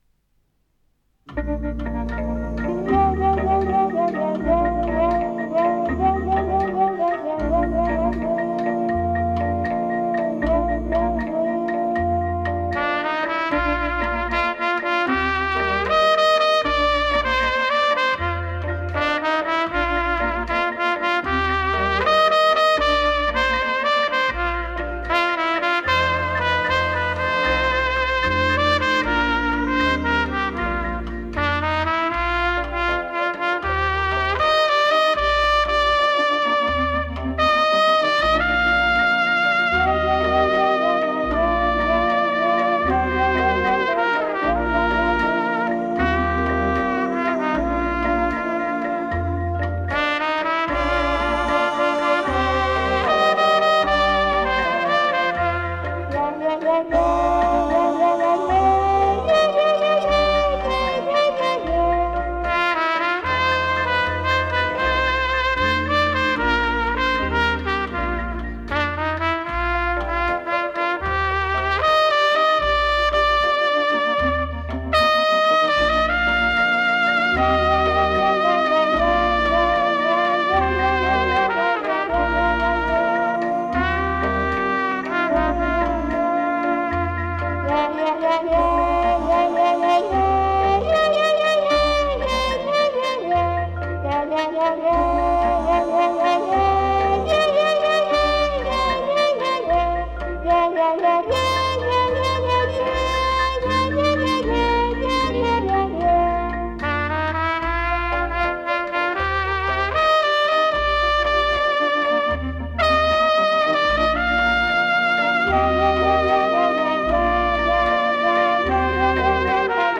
Блюз (blues, от англ. blue devils)